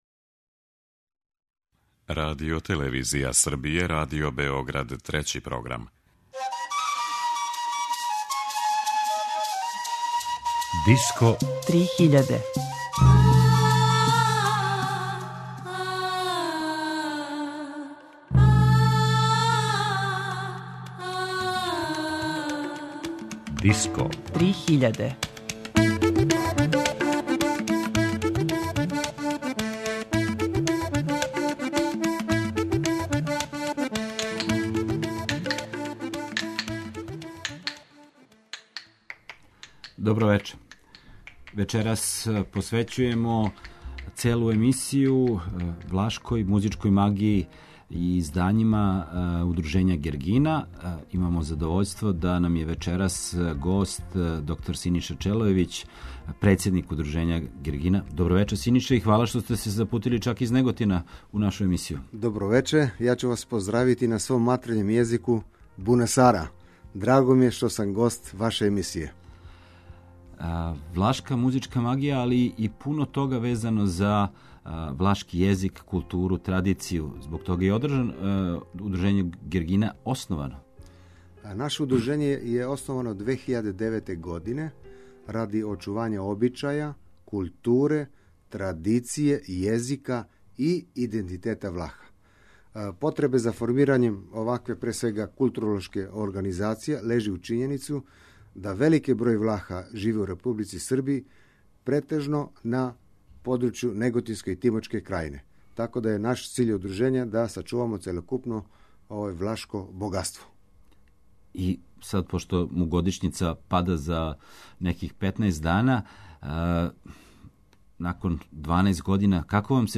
Disco 3000 је емисија посвећена world music сцени, новитетима, трендовима, фестивалима и новим албумима.